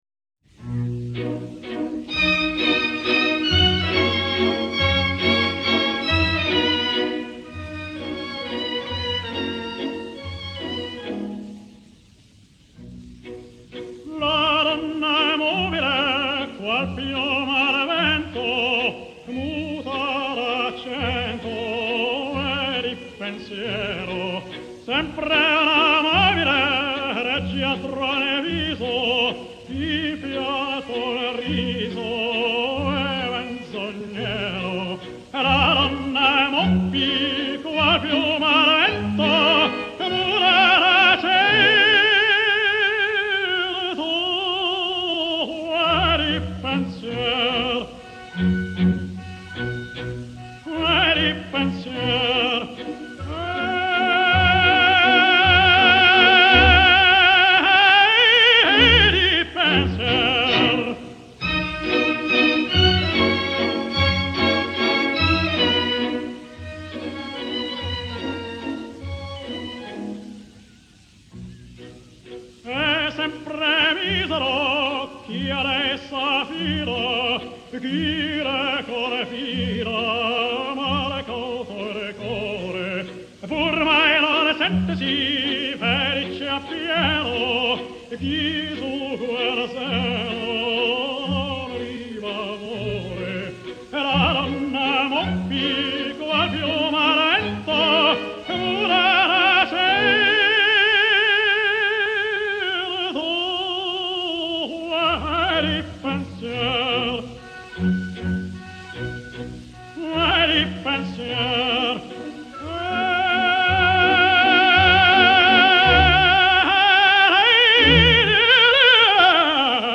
– 1942: A murit (în Elveţia, într-un lagăr de refugiaţi austrieci) tenorul de origine română Joseph Schmidt (n. 1904, în Bucovina)
Joseph Schmidt (n. 4 martie 1904, Davideni, lângă Cernăuţi, Bucovina, Austro-Ungaria, azi Ucraina – d. 16 noiembrie 1942, Elveţia) a fost un tenor român şi cantor evreu de faimă internaţională.
Joseph Schmidt (Selectiuni-inregistrari EMI)-La donna e mobile (Arch, David / Piave, / Verdi)
La-donna-e-mobile-Verdi-Duca-JosephSchmidt.mp3